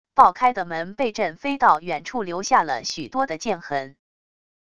爆开的门被震飞到远处留下了许多的剑痕wav音频